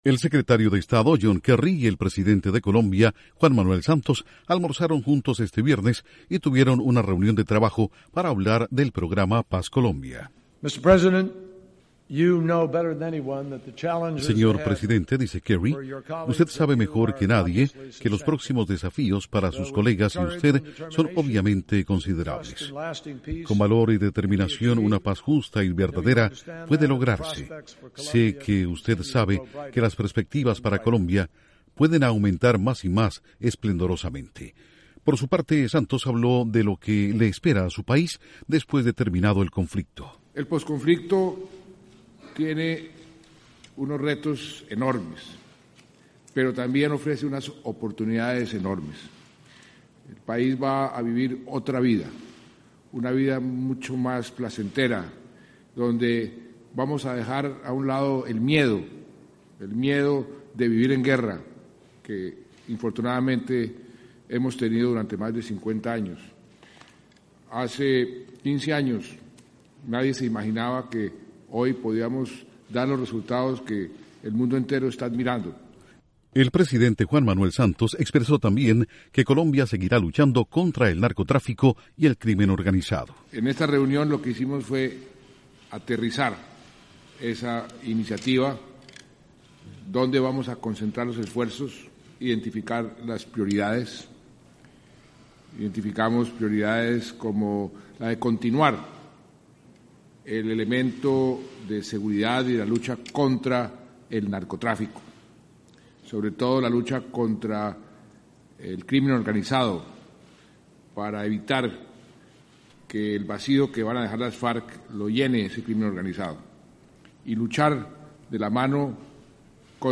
Al concluir su visita a Washington el presidente de Colombia afirma que su país seguirá luchando contra el narcotráfico y el crimen organizado. Informa desde la Voz de América en Washington